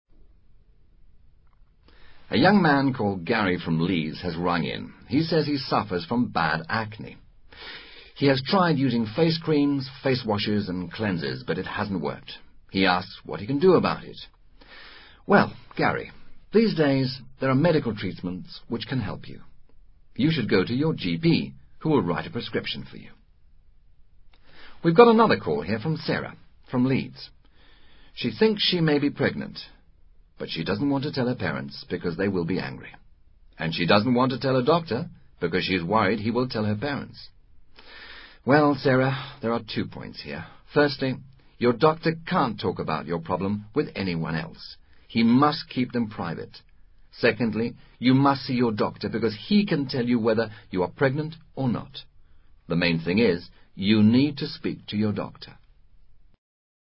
Recreando un programa televisivo, un médico aconseja a dos jóvenes espectadores la solución de sus respectivos problemas.